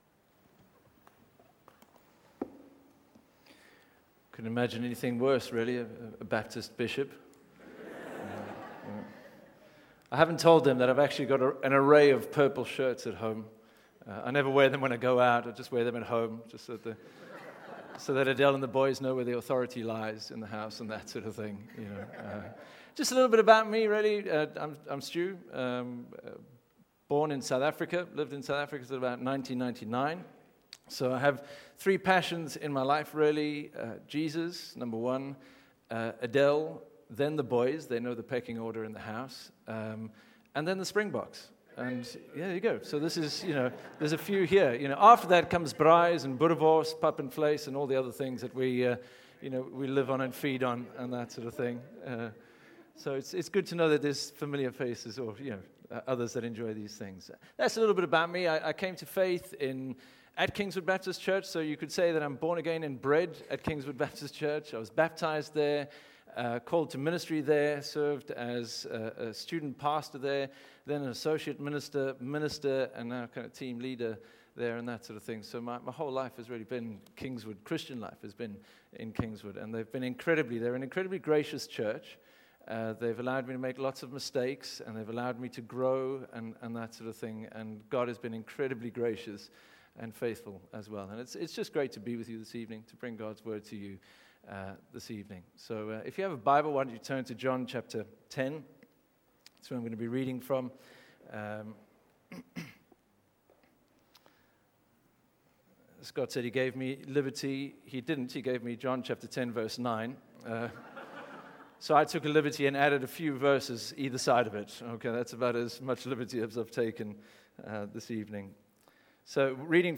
A message from the series "I am."